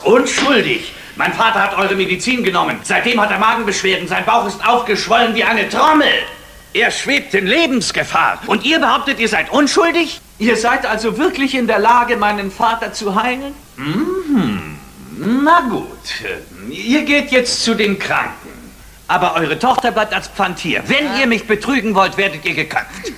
Als ich davon las, recherchierte ich ein wenig und war hellauf begeistert, als ich auf Youtube eine alte Fernseh-Aufzeichnung fand, die dort jemand vor 3 Jahren online stellte.